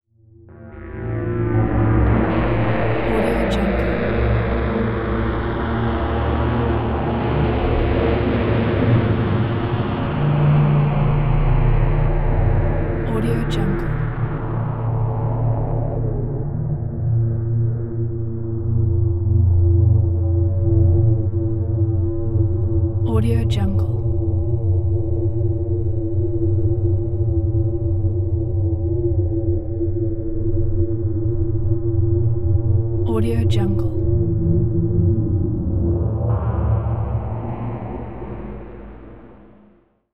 دانلود افکت صدای بازی های فضایی
افکت صدای بازی های فضایی یک گزینه عالی برای هر پروژه ای است که به صداهای آینده نگر و جنبه های دیگر مانند تونال، محیط و محیط نیاز دارد.
Sample rate 16-Bit Stereo, 44.1 kHz
Looped No